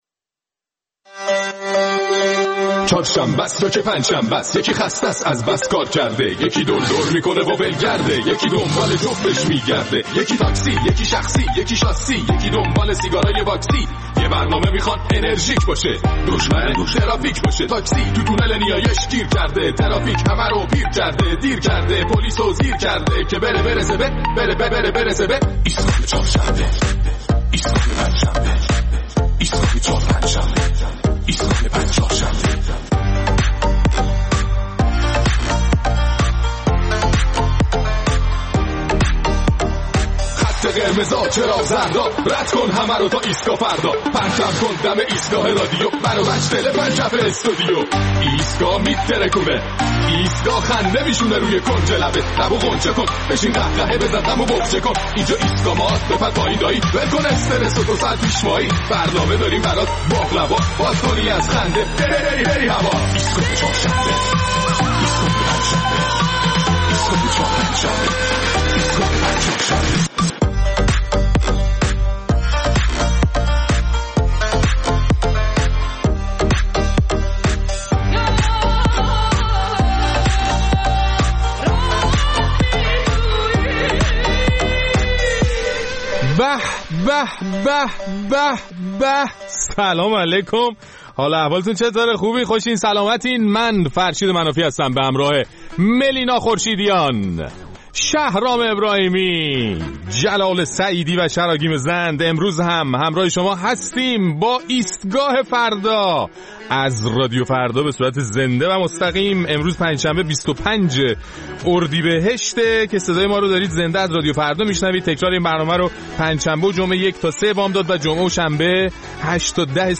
در این برنامه ادامه نظرات شنوندگان ایستگاه فردا را در مورد مقوله عادت و عادت‌های دوران کرونا می‌شنویم.